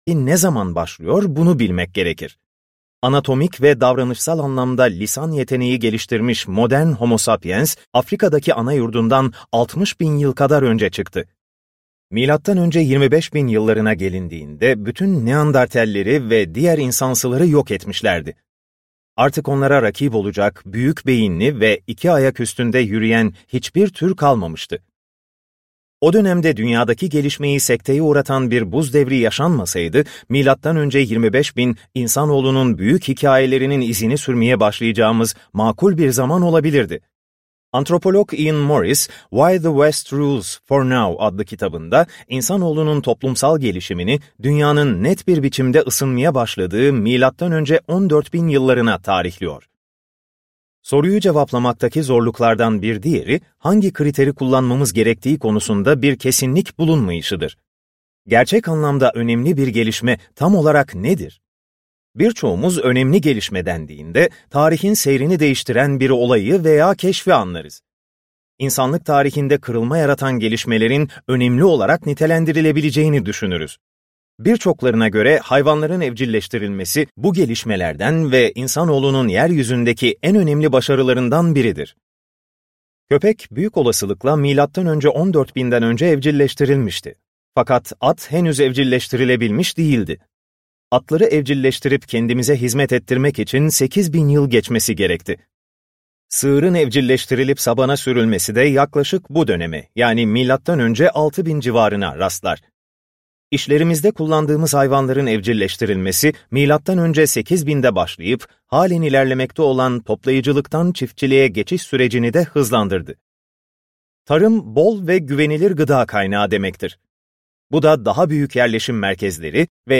the Second Machine Age - Seslenen Kitap